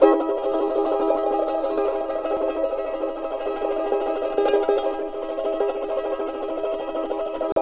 CHAR D MN TR.wav